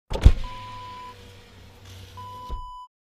Car Power Window Open Sound Effect Wav
Description: Car power window opened
Properties: 48.000 kHz 16-bit Stereo
A beep sound is embedded in the audio preview file but it is not present in the high resolution downloadable wav file.
car-window-open-preview-1.mp3